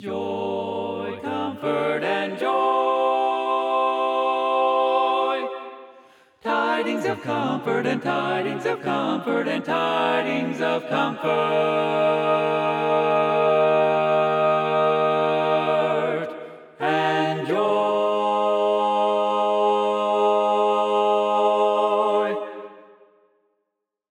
Key written in: G Minor
Type: Barbershop